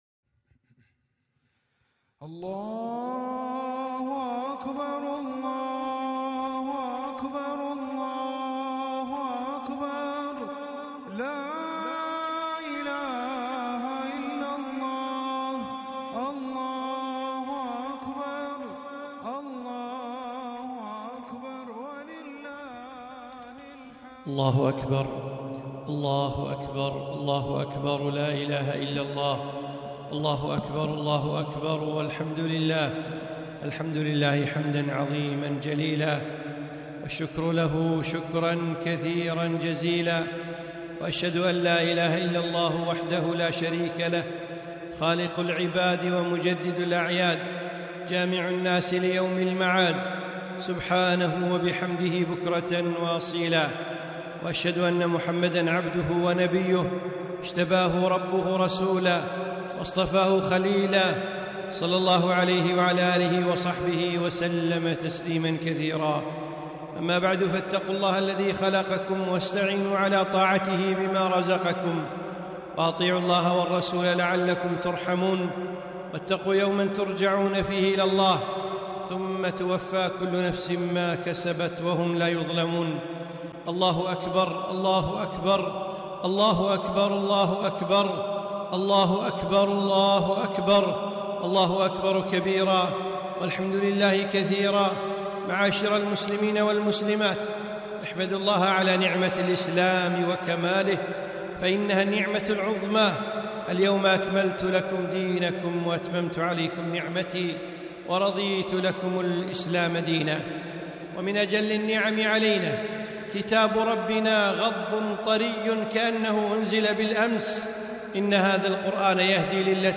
خطبة عيد الأضحى ((فصل لربك وانحر)) ١٠ ذي الحجة ١٤٤٢هـ